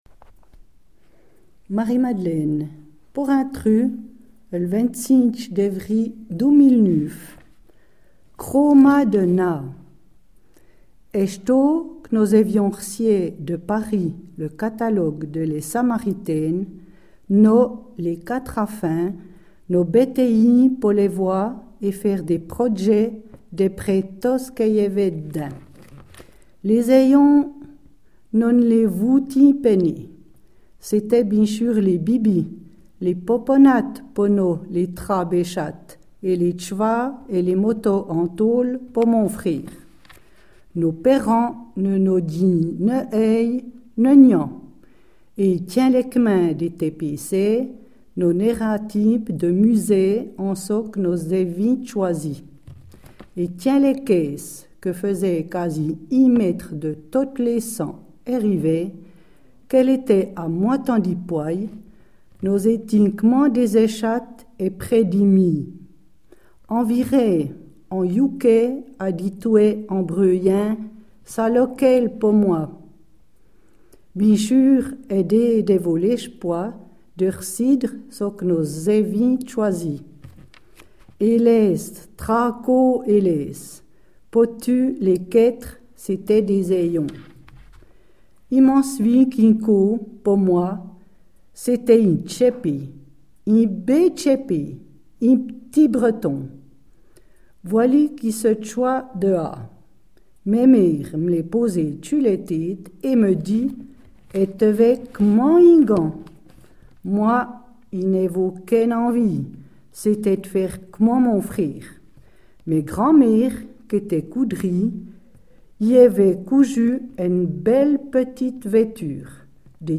Patois d’origine de Soubey, lu avec un accent vadais.